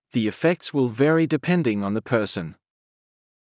ｼﾞ ｴﾌｪｸﾂ ｳｨﾙ ﾊﾞﾘｰ ﾃﾞｨﾍﾟﾝﾃﾞｨﾝｸﾞ ｵﾝ ｻﾞ ﾊﾟｰｿﾝ